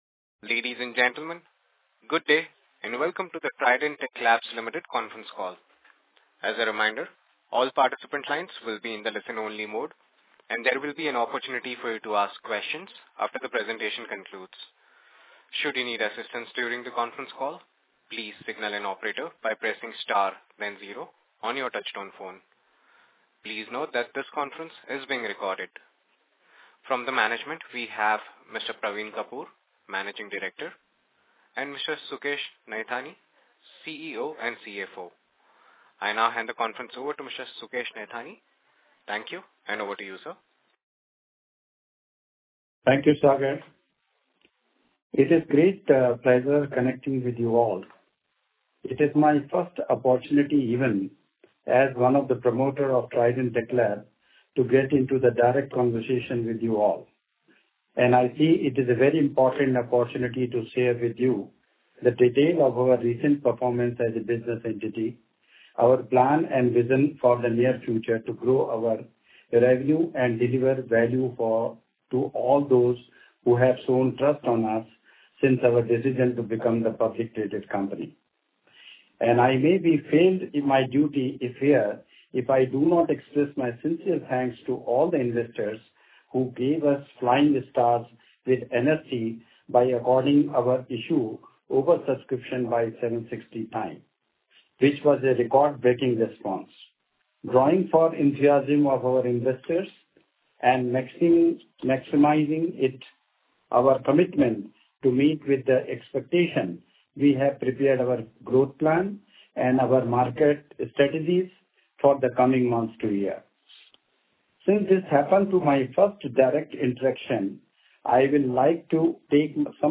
Audio Recording of Earnings Call Dated 15.11.2024